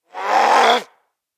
DayZ-Epoch/SQF/dayz_sfx/zombie/spotted_2.ogg at f5dff8d0b7421e2b555cd0e77f67ce4aee2c37cc